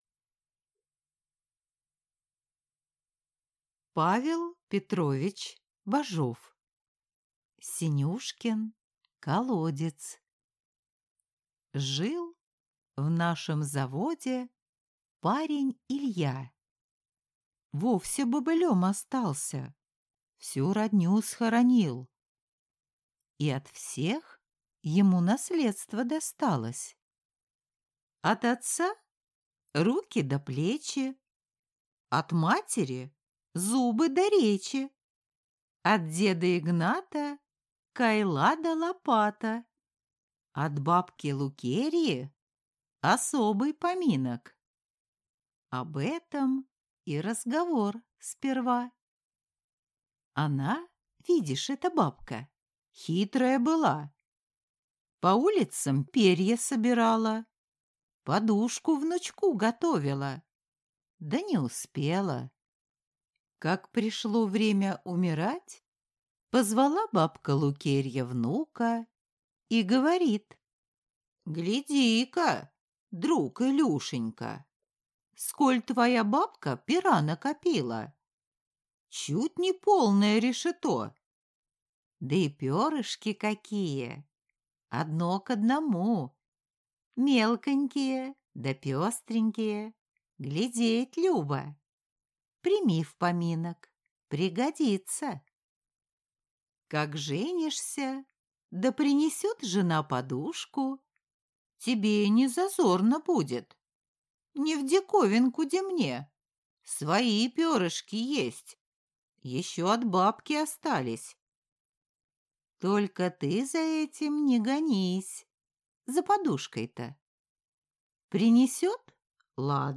Аудиокнига Синюшкин колодец | Библиотека аудиокниг